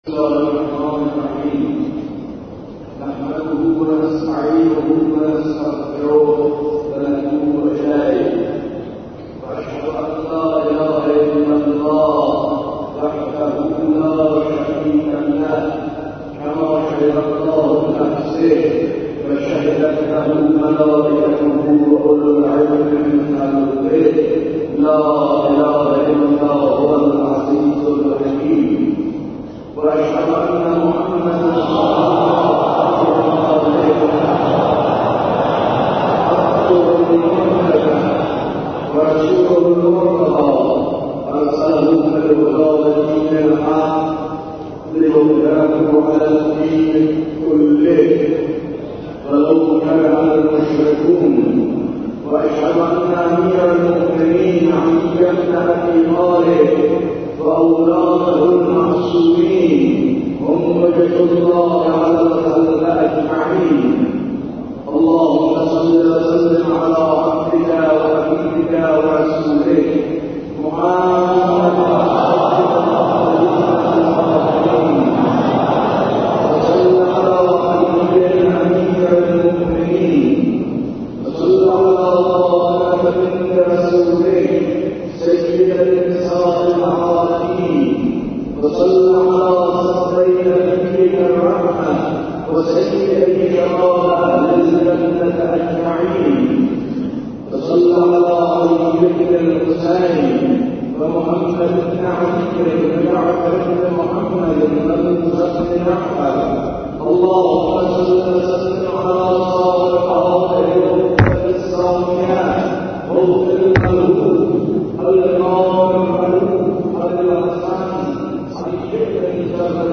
خطبه دوم 23 خرداد.mp3
خطبه-دوم-23-خرداد.mp3